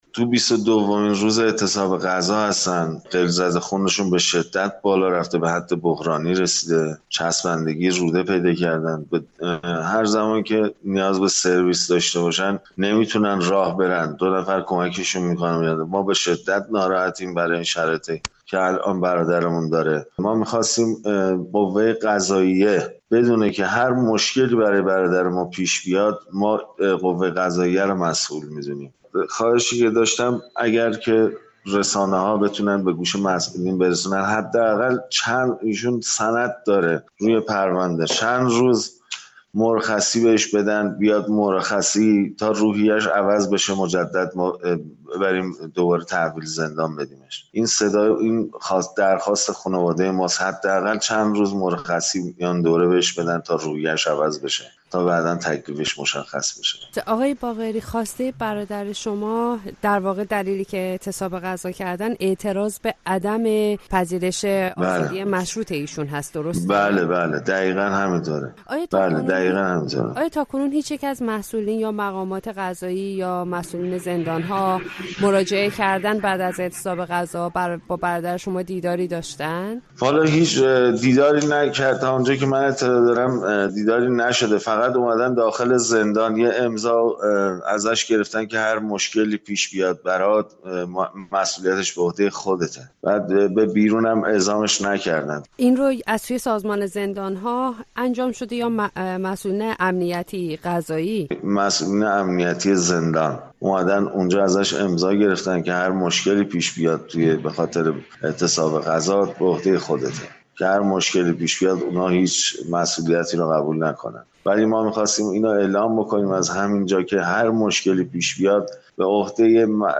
در گفت‌وگو با رادیو فردا